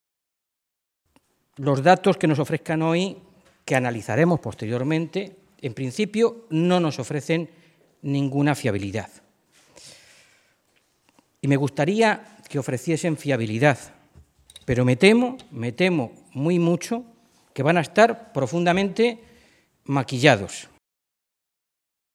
Mora se pronunciaba de esta manera esta mañana en Toledo, en una comparecencia ante los medios de comunicación poco antes de que el consejero de Sanidad, José Ignacio Echániz, ofreciera una rueda de prensa en la que va a presentar la nueva página web del Servicio de Salud de Castilla-La Mancha y va a ofrecer los datos sobre las listas de espera en la región, que el Gobierno de Cospedal dejó de publicar en enero de este año.
Cortes de audio de la rueda de prensa